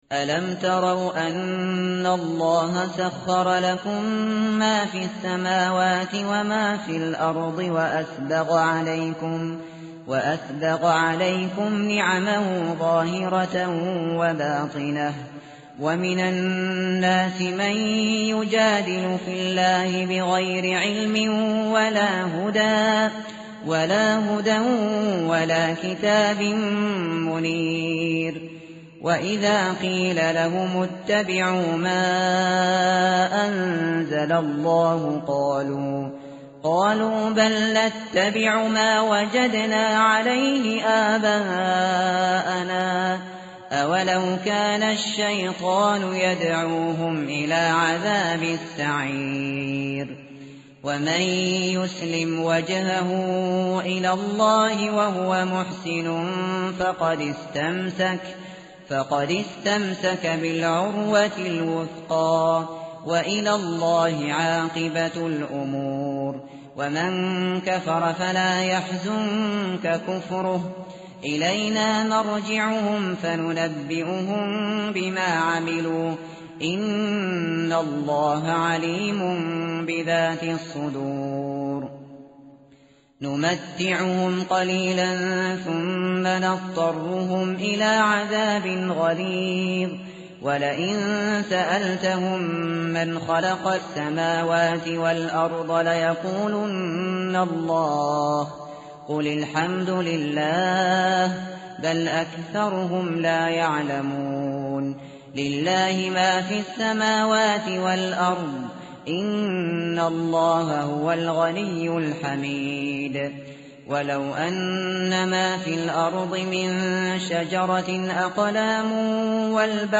متن قرآن همراه باتلاوت قرآن و ترجمه
tartil_shateri_page_413.mp3